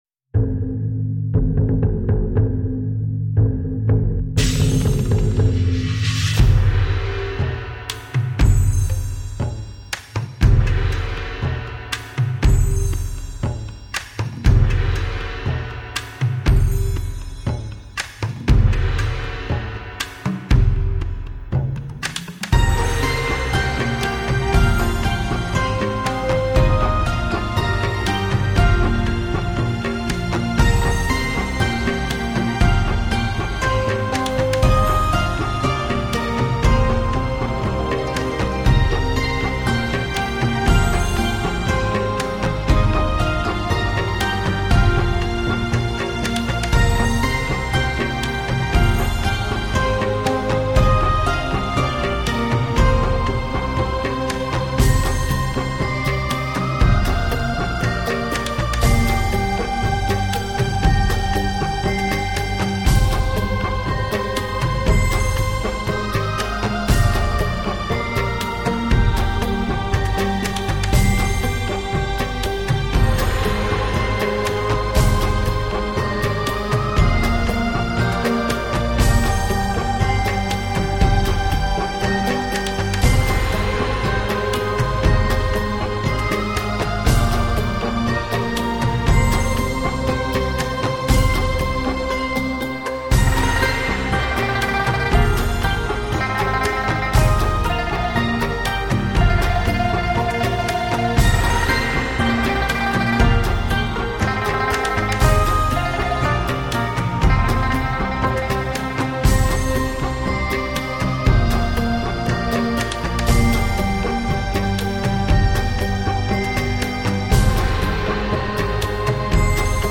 东方的神秘新世纪音乐，再繁杂的心也能平静。
融合东方旋律与西方编曲理念 感受东方民族音乐神秘的魅力
高保真录制的千年铜鼓的乐音
听千年前汉代铜鼓发出的声音，能否看到千年前？